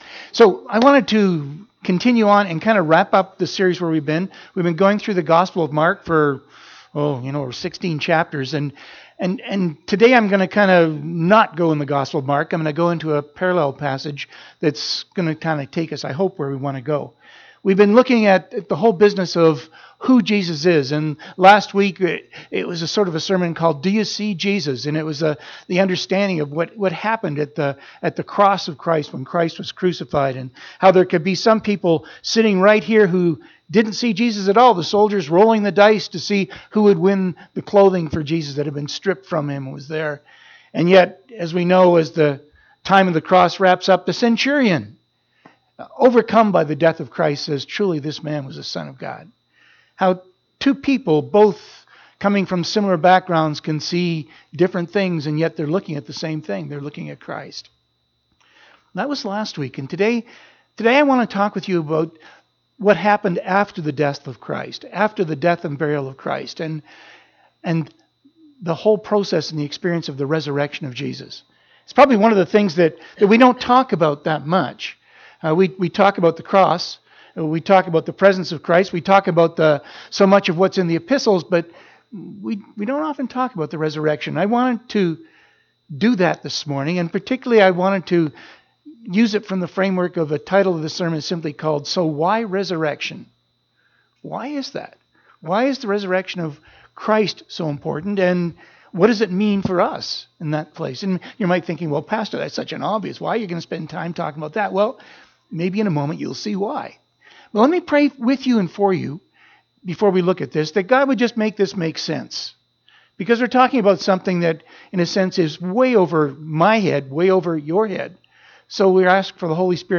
So Why Resurrection? | Sermons | Resources